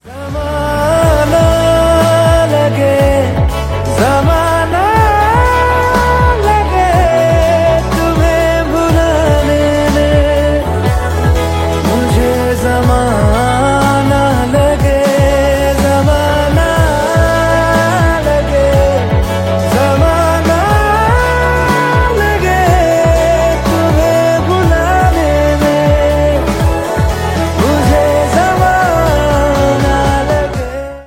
Bollywood Songs